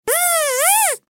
دانلود صدای حشره 3 از ساعد نیوز با لینک مستقیم و کیفیت بالا
جلوه های صوتی